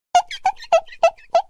Funny Women Meme Effect sound effects free download